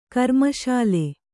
♪ karmaśale